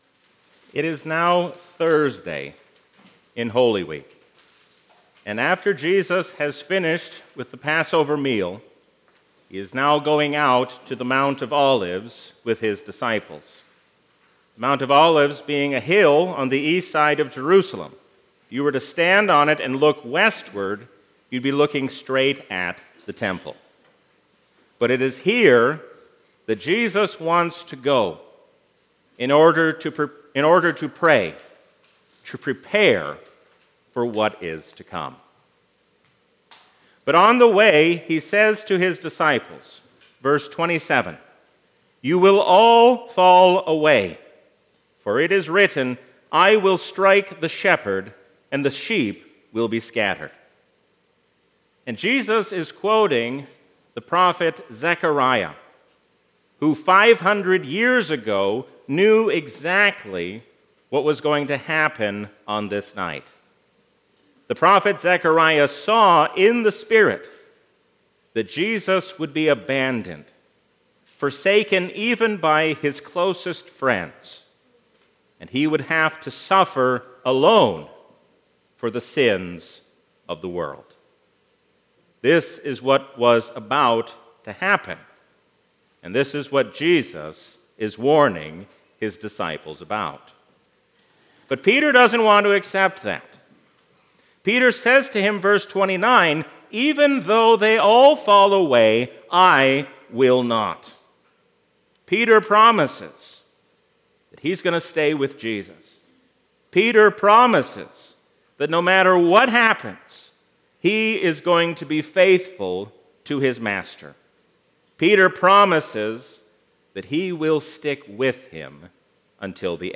A sermon from the season "Trinity 2024." Let us not be double minded, but single minded in our devotion toward God.